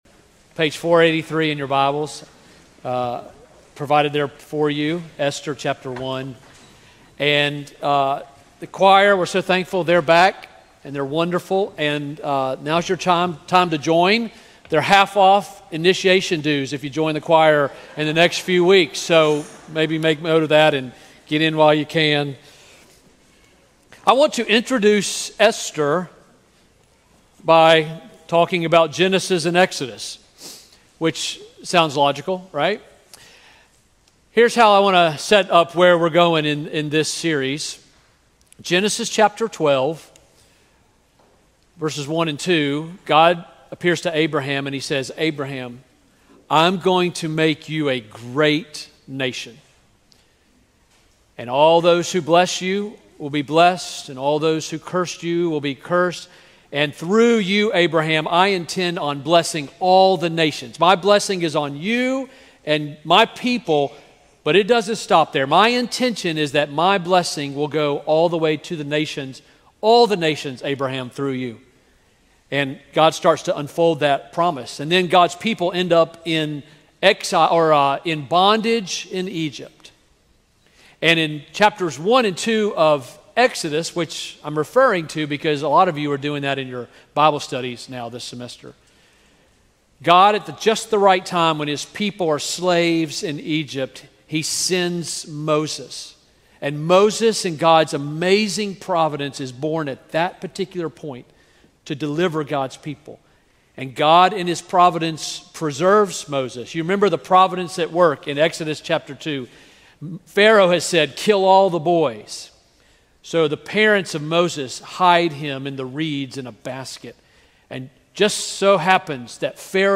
A sermon from the series "Esther."